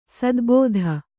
♪ sadbōdha